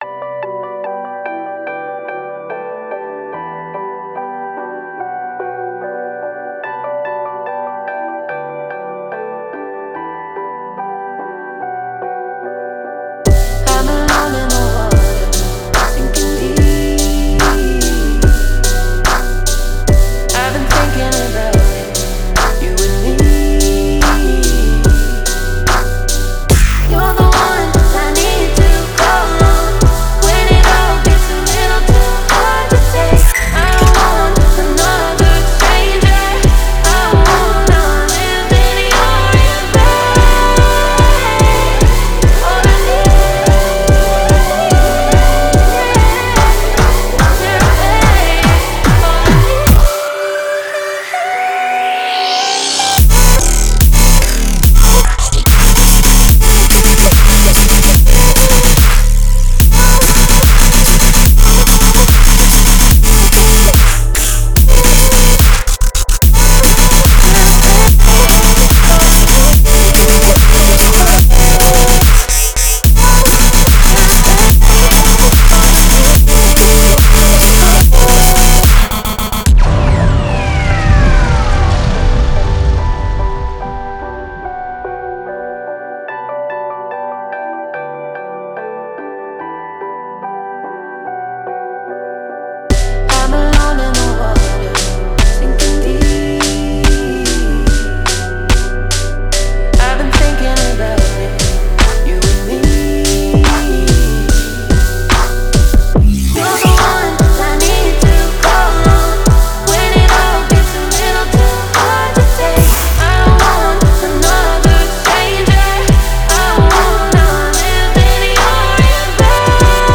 Future Riddim
02:52 BPM: 145 Download